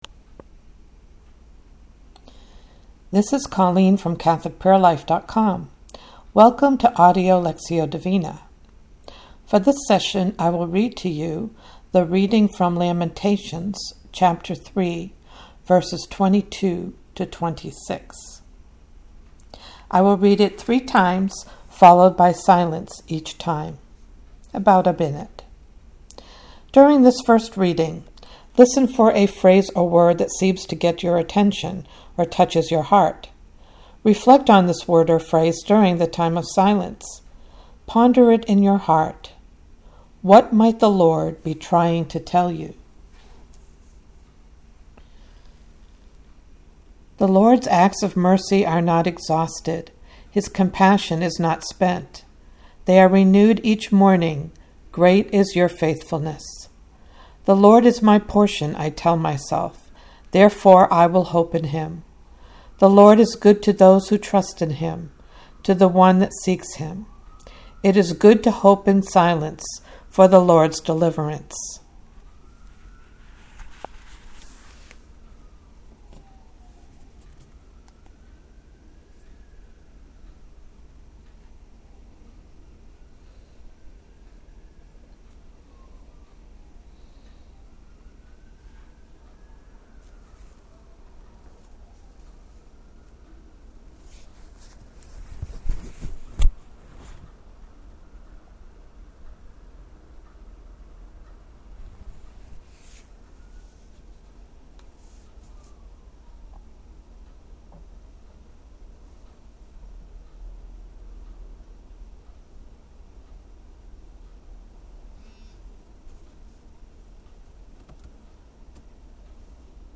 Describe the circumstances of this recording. I will read the scripture verse 3 times with a minute of silence in between.